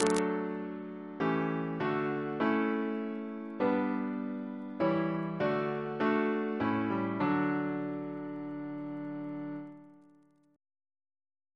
Single chant in D Composer: John Jones (1757-1833), Organist of St. Paul's Cathedral Reference psalters: ACB: 169; OCB: 208